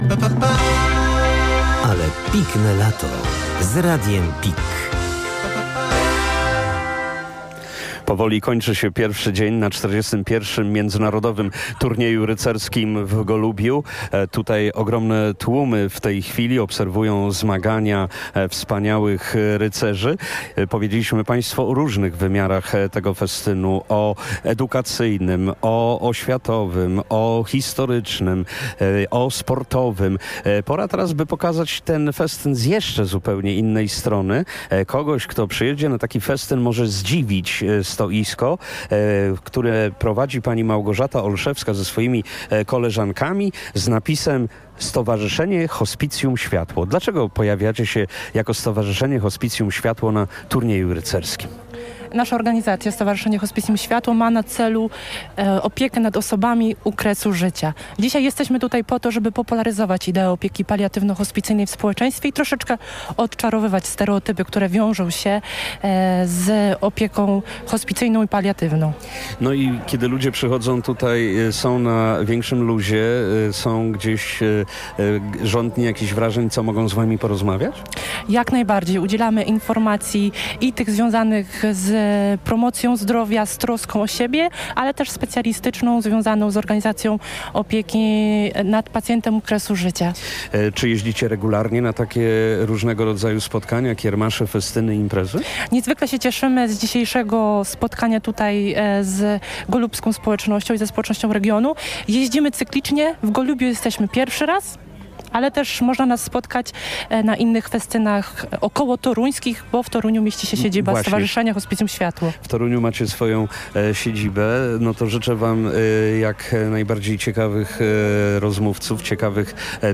O aktywności Stowarzyszenia Hospicjum Światło na 41 Międzynarodowym Turnieju Rycerskim można było również usłyszeć na antenie Radia PiK, jednego z patronów medialnych wydarzenia: